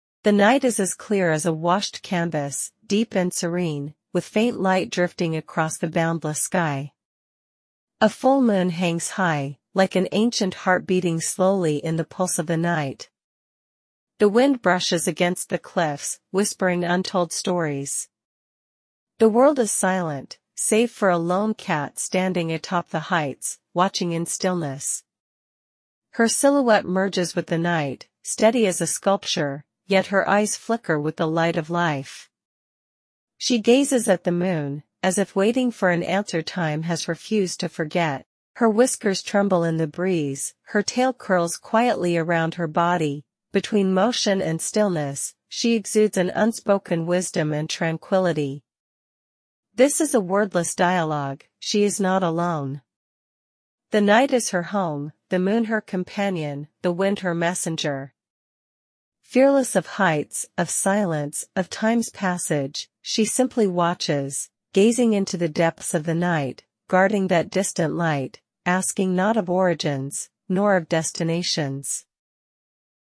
English audio guide